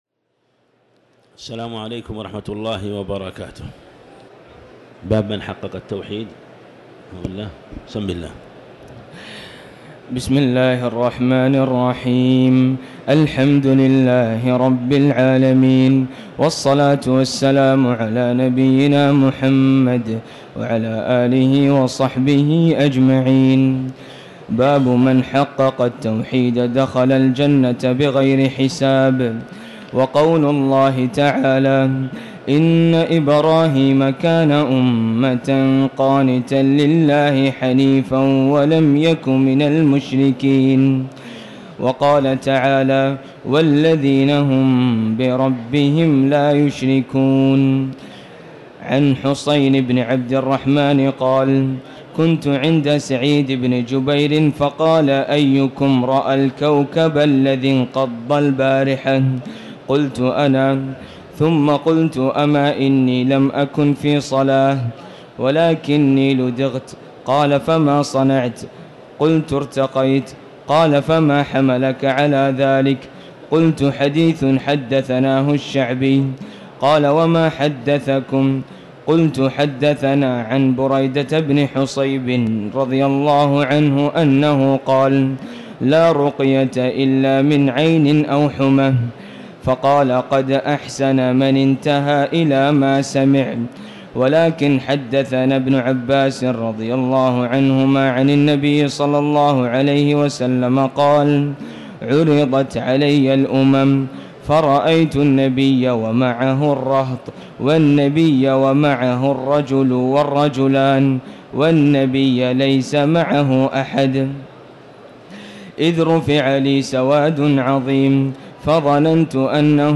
تاريخ النشر ٤ رمضان ١٤٤٠ هـ المكان: المسجد الحرام الشيخ